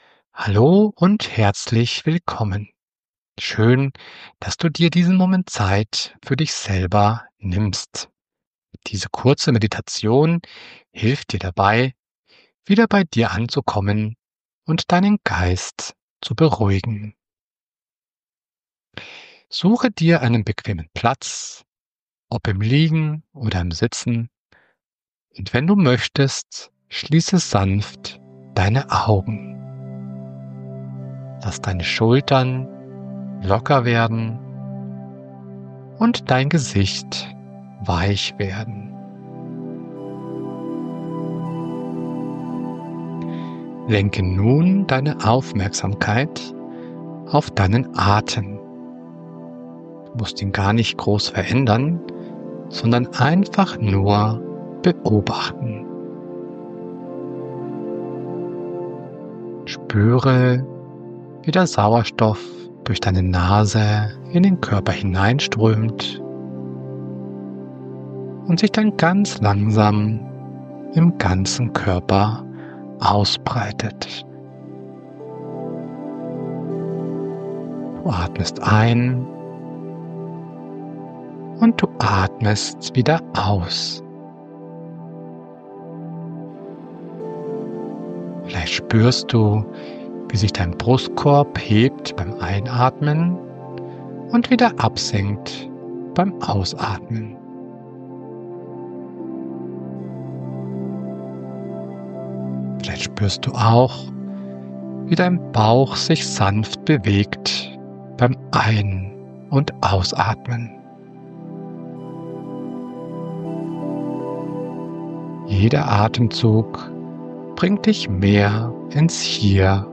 Eine angeleitete Meditation zum Runterkommen